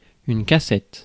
Ääntäminen
Synonyymit coffret Ääntäminen France: IPA: /ka.sɛt/ Haettu sana löytyi näillä lähdekielillä: ranska Käännös Substantiivit 1. treasure box 2. any small box 3. cassette tape 4. cassette Suku: f .